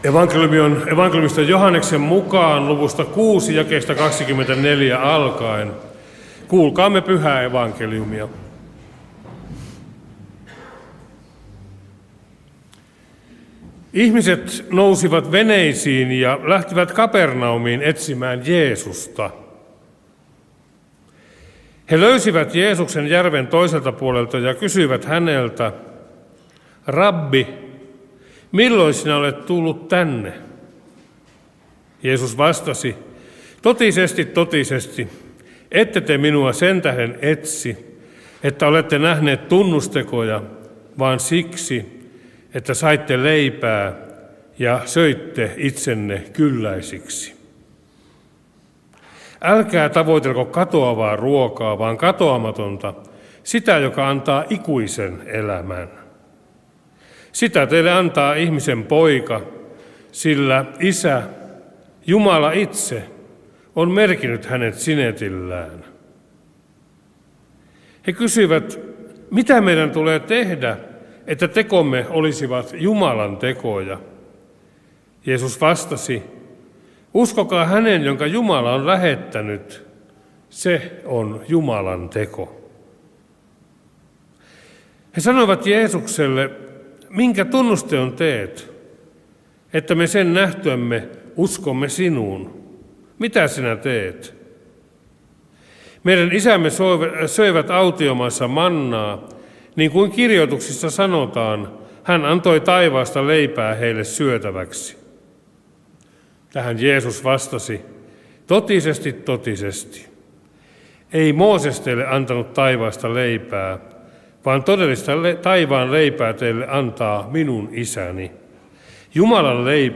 saarna